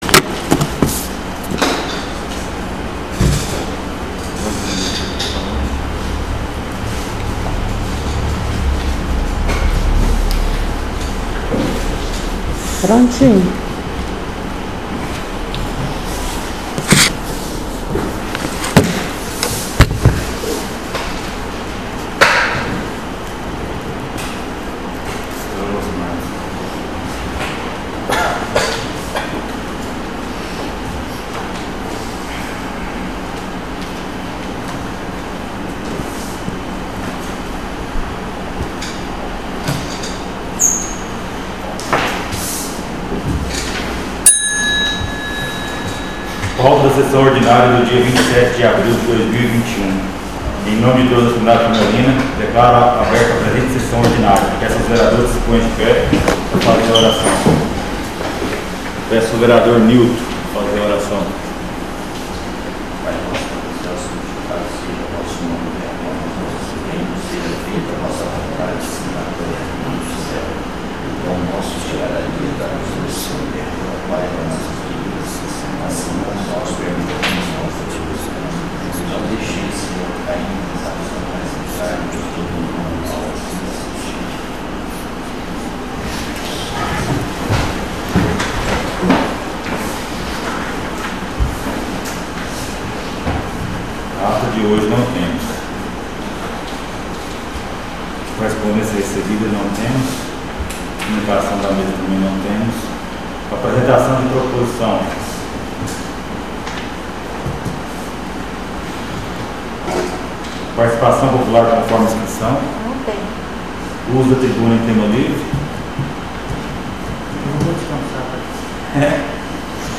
SESSÃO ORDINÁRIA DIA 27/04/2021